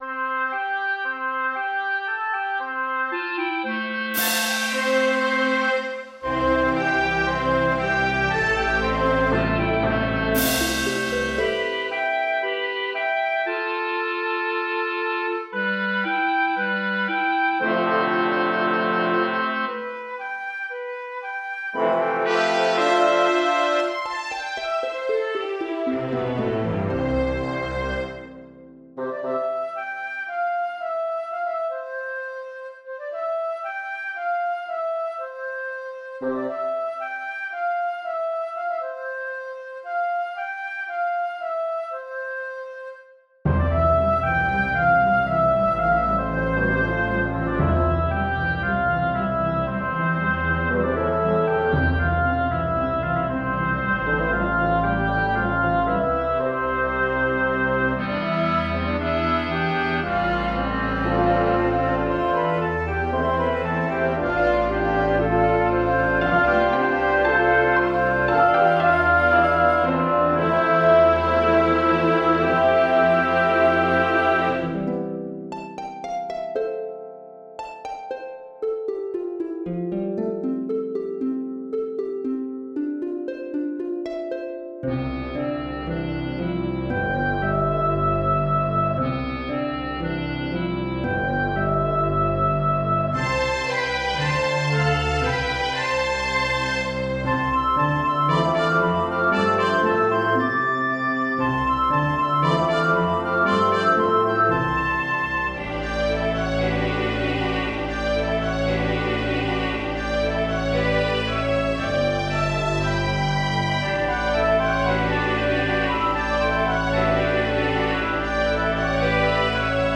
Tone Poems For Orchestra.